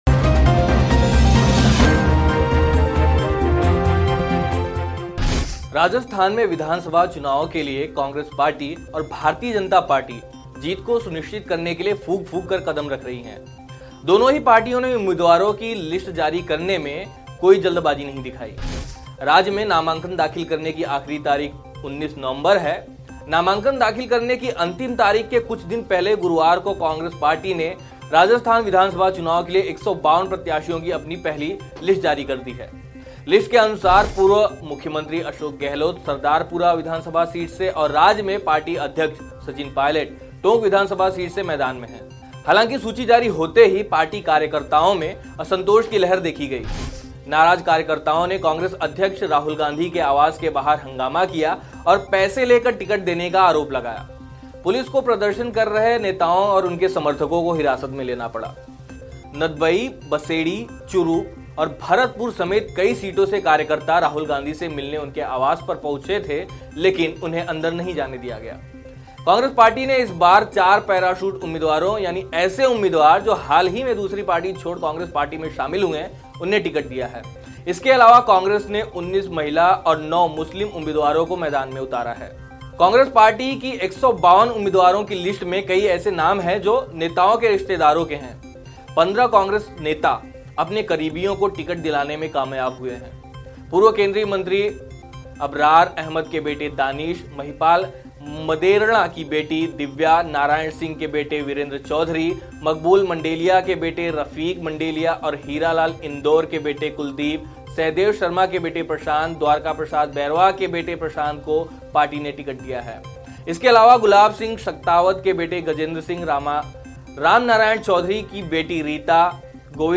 न्यूज़ रिपोर्ट - News Report Hindi / राजस्थान चुनाव: इन कांग्रेसी नेताओं के रिश्तेदारों को मिला टिकट